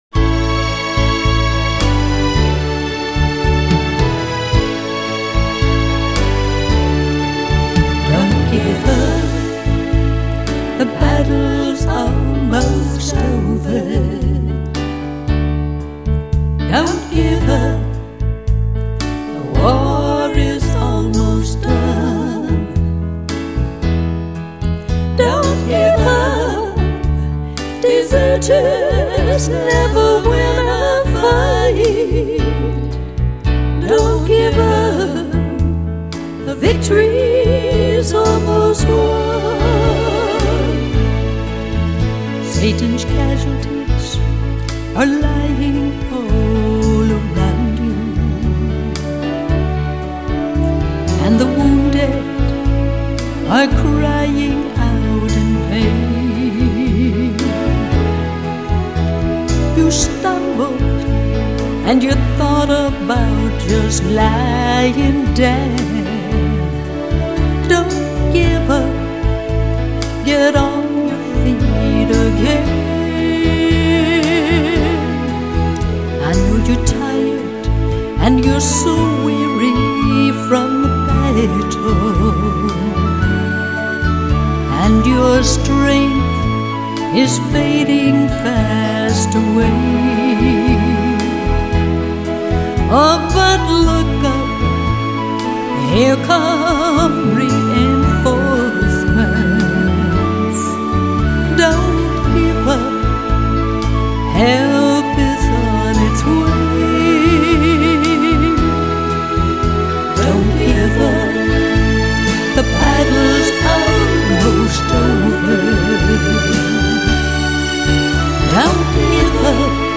SONG: DON’T GIVE UP!